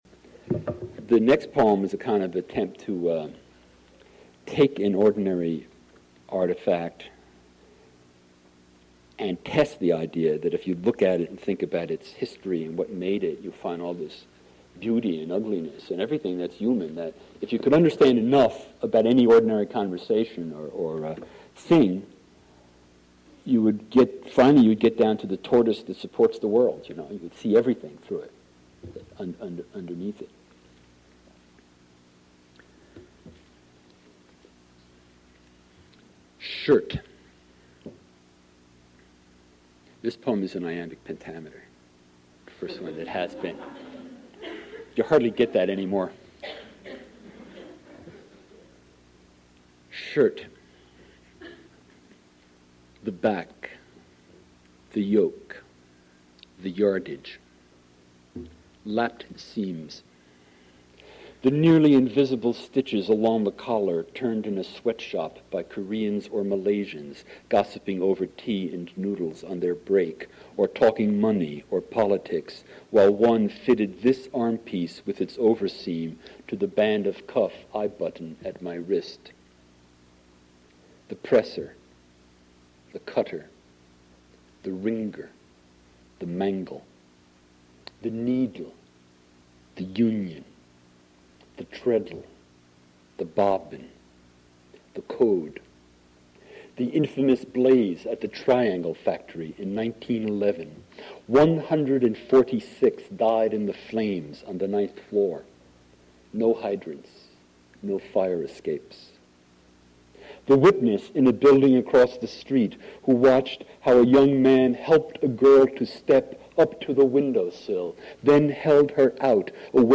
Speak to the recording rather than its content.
Modern Languages Auditorium